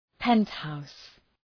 {‘pent,haʋs}